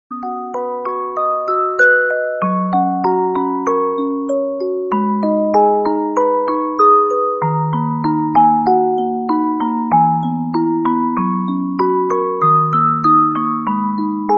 3 – نغمة عزف بيانو (piano_ringtone)